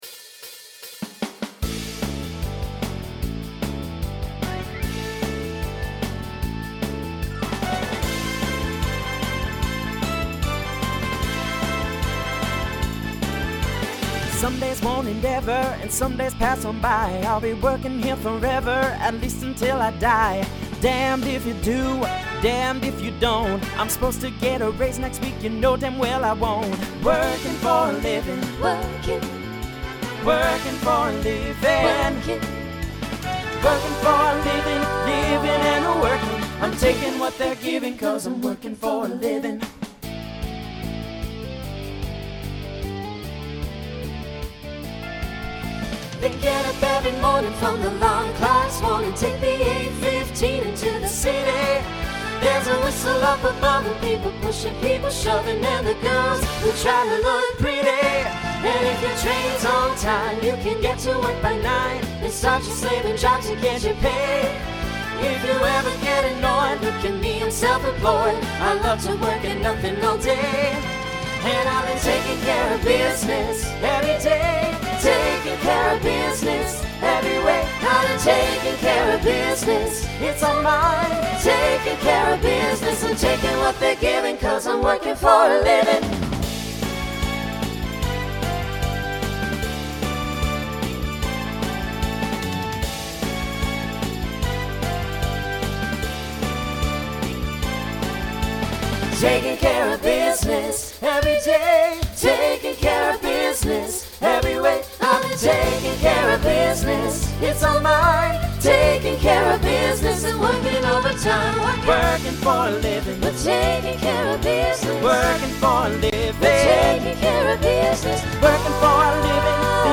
New TTB voicing for 2026.
TTB Instrumental combo Genre Rock Decade 1970s